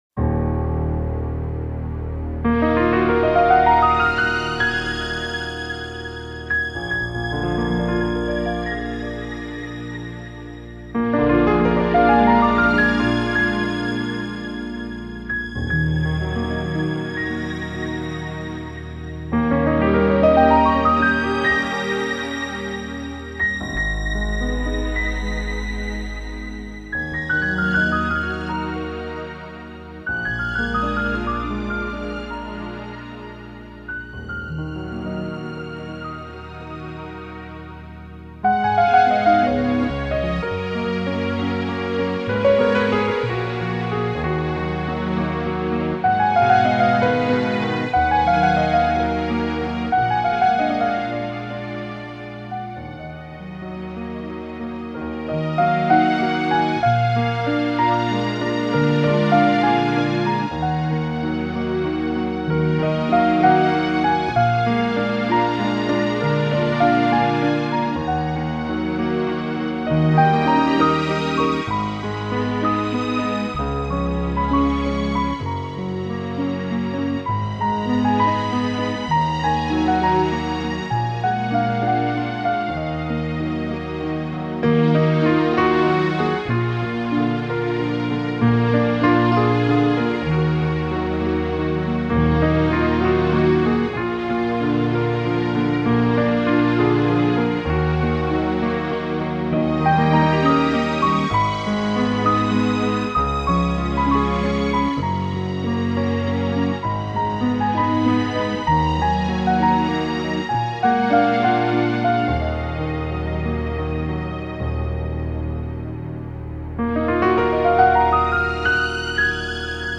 Genre:New Age, Instrumental, Neo-Classical, Piano